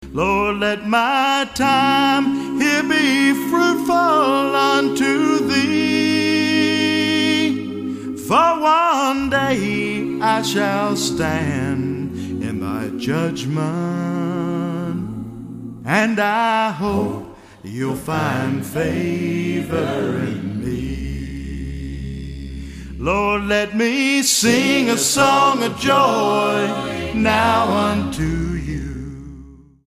STYLE: Country
Uncomplicated country-tinged pop